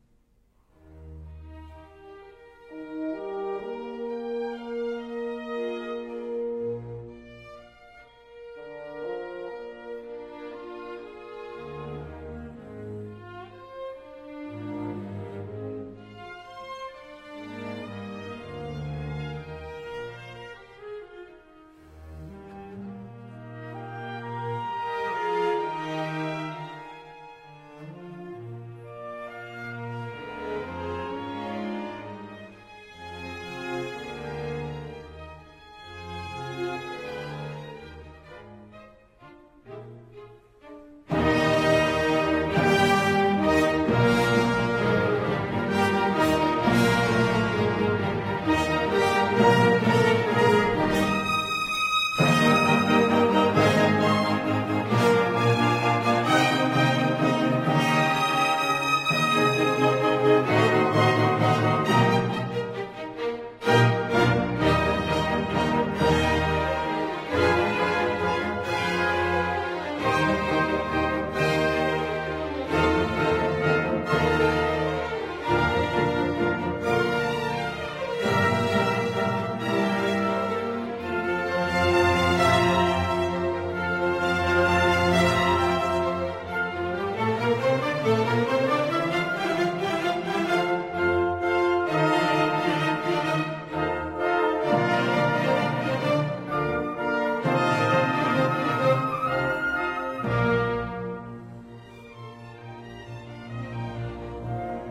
Dotted half note = 42-44